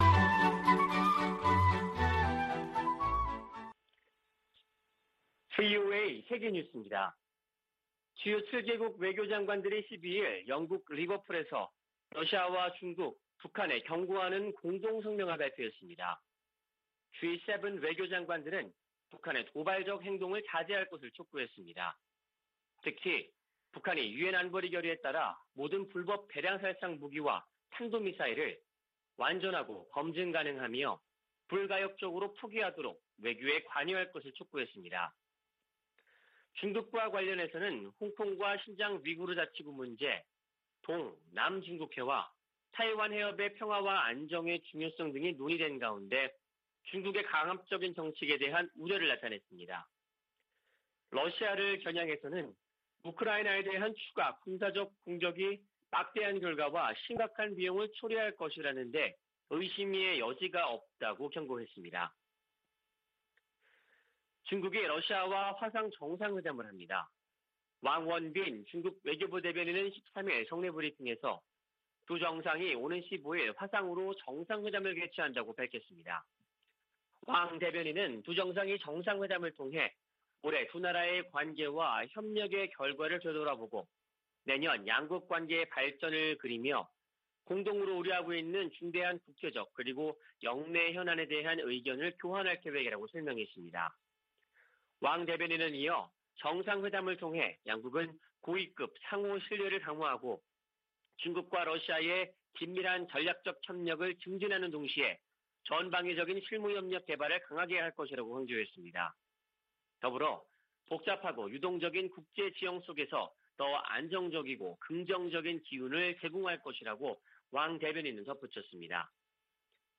VOA 한국어 아침 뉴스 프로그램 '워싱턴 뉴스 광장' 2021년 12월 14일 방송입니다. 미 재무부가 리영길 국방상 등 북한과 중국, 미얀마 등에서 심각한 인권 유린을 자행한 개인과 기관들에 경제 제재를 부과했습니다. 문재인 한국 대통령은 베이징 동계 올림픽 외교적 보이콧을 고려하지 않고 있다면서 중국의 건설적 역할의 필요성을 강조했습니다. 미국이 북한에 코로나 백신을 주겠다고 제안한다면 북한이 대화의 장으로 나올 수도 있을 것이라고 한국 국가정보원장이 말했습니다.